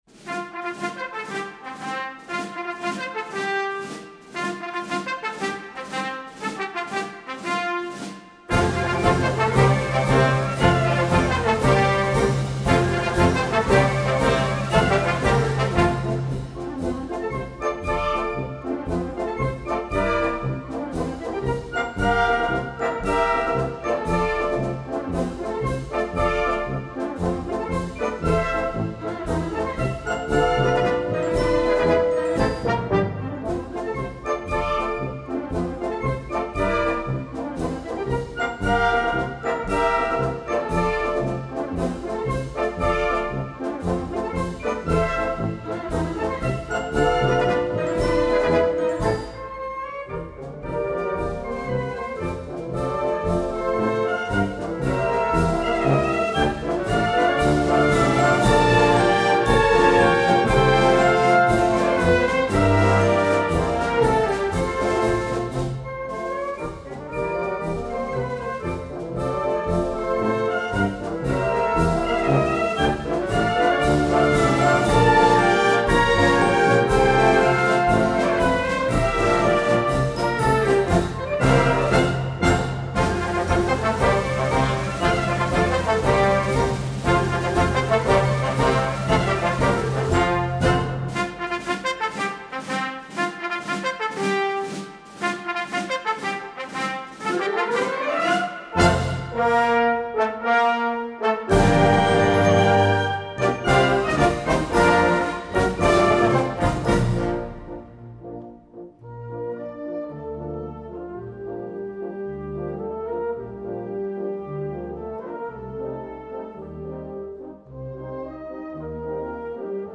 Gattung: Konzertmarsch für Blasorchester
Besetzung: Blasorchester